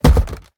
fallingBlock.ogg